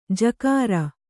♪ jakāra